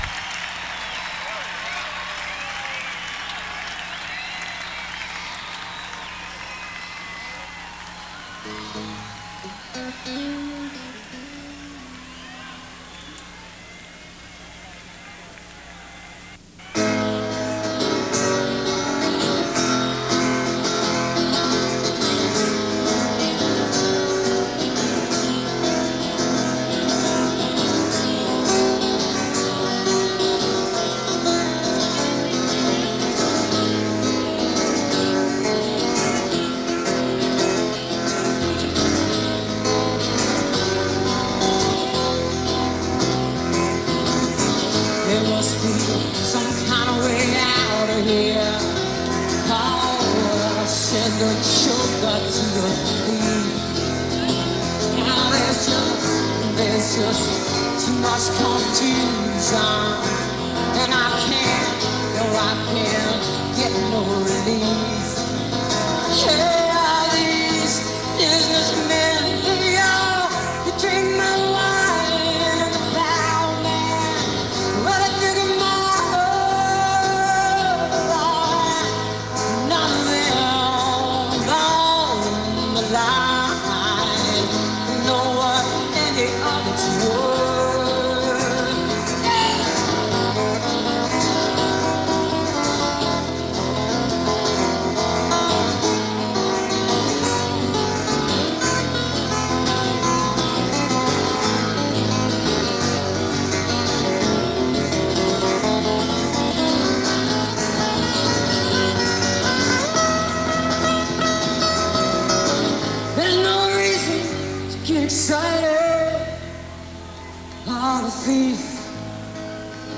autzen stadium - eugene, oregon